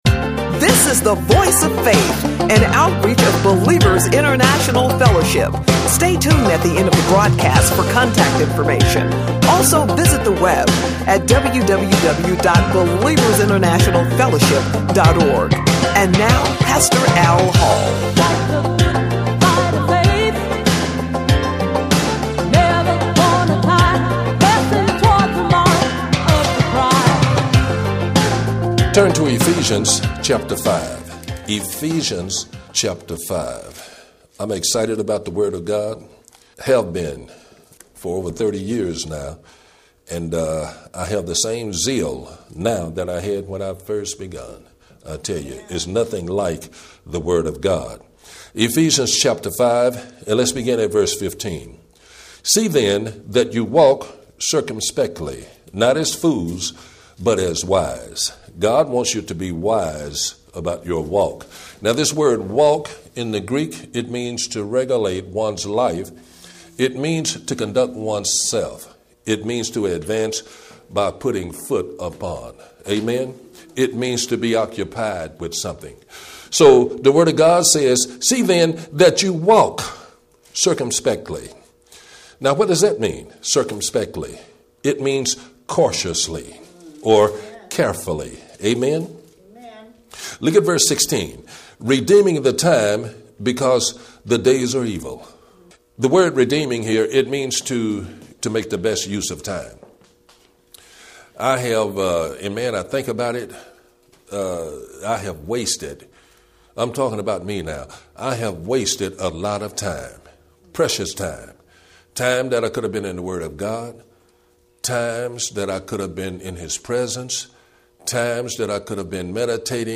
3 Share this sermon